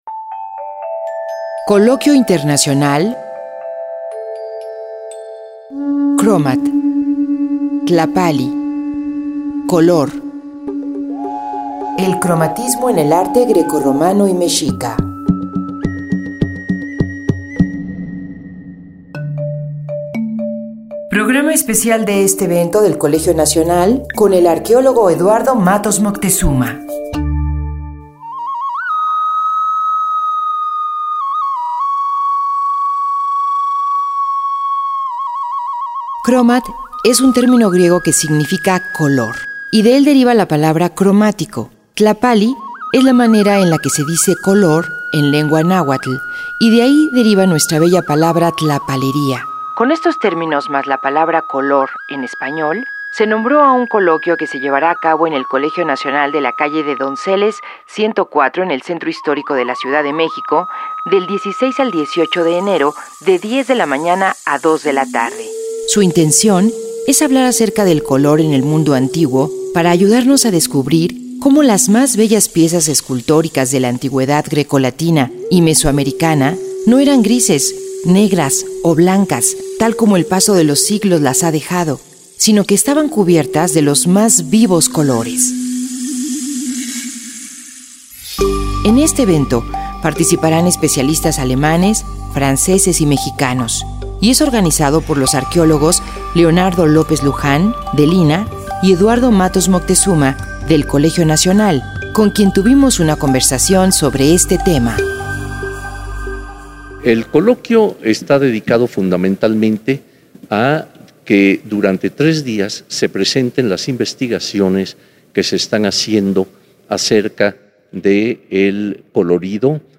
Una plática con el arqueólogo Eduardo Matos Moctezuma sobre el Coloquio Internacional El Cromatismo en el arte Grecorromano y Mexica.